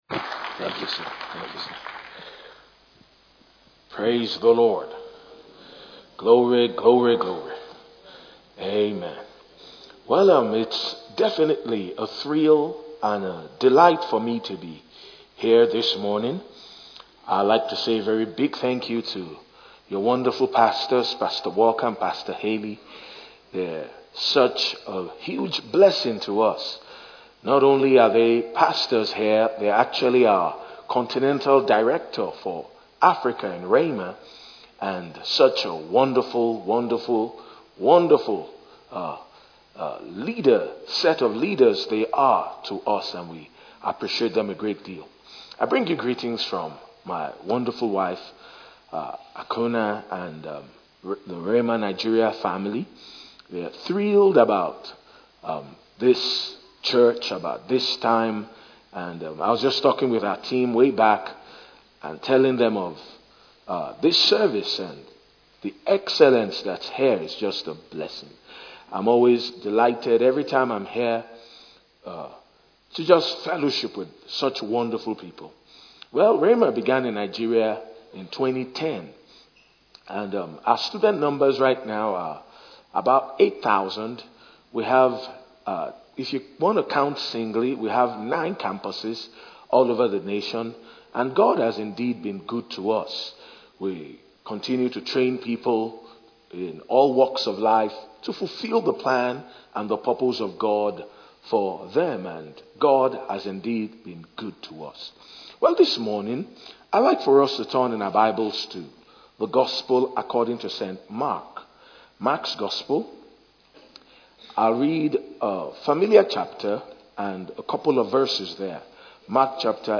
A message from the series "Individual Sermons."
From Series: "Individual Sermons"